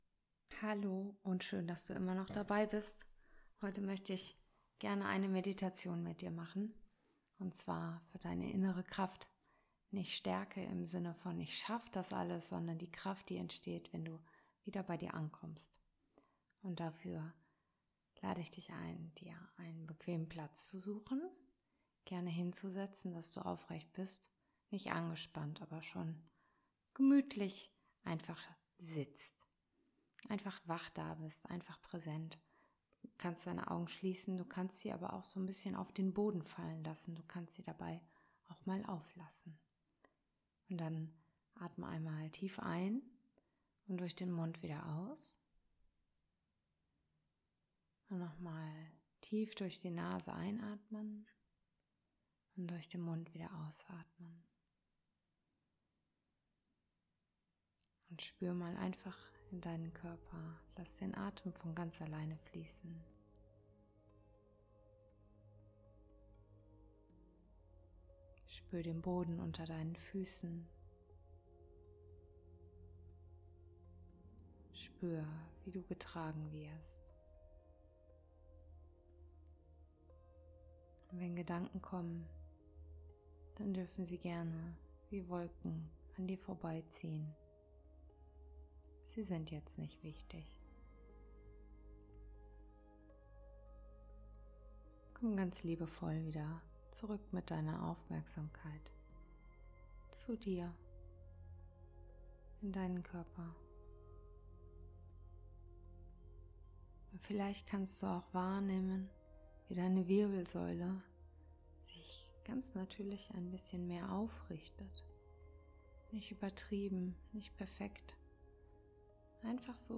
Eine Meditation für deinen inneren Übergang